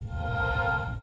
Index of /App/sound/monster/ice_snow_monster
walk_1.wav